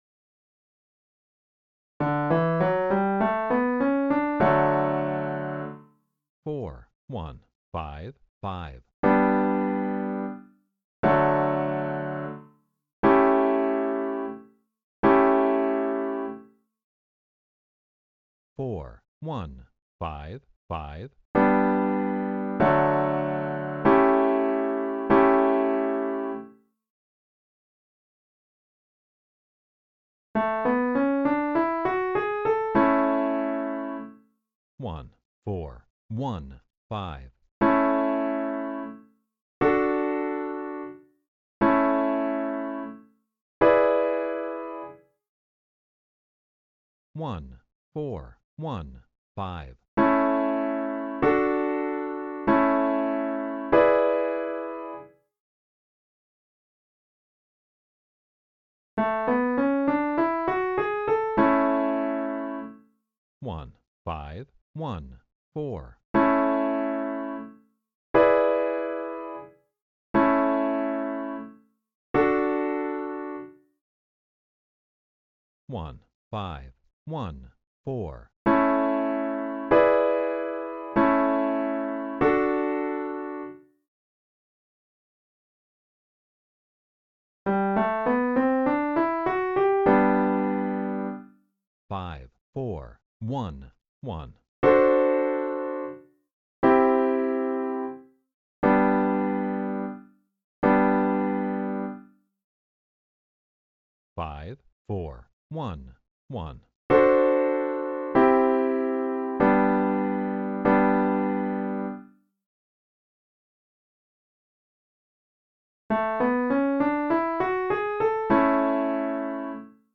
Each time you will hear the scale and then a progression is played. It is then announced so you know which chords you’re hearing.
Training Track: I, IV, V7
Training_Exercise_1._I__IV__V7_Easy.mp3